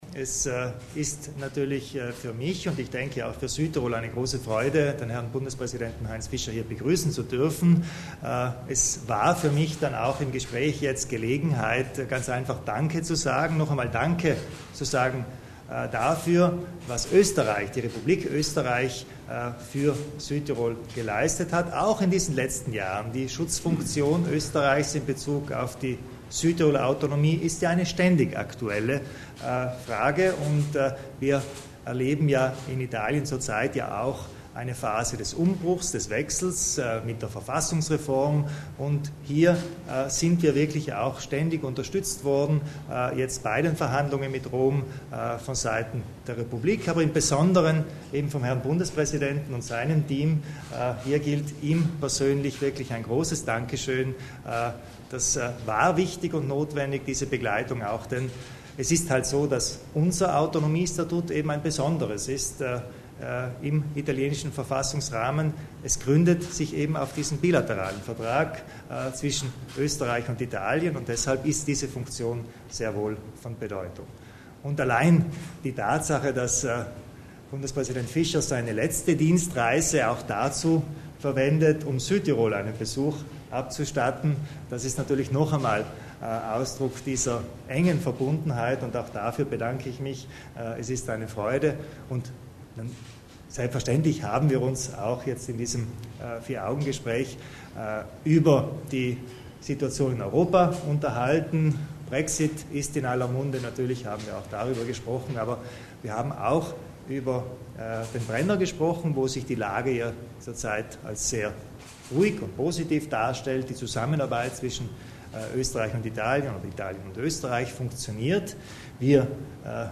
Der österreichische Bundespräsident Fischer zählt die behandelten Themen beim Treffen mit Landeshauptmann Kompatscher zusammen